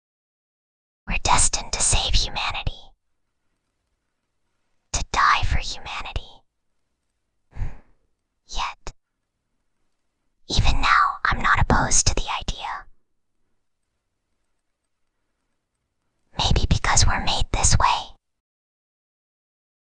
Whispering_Girl_11.mp3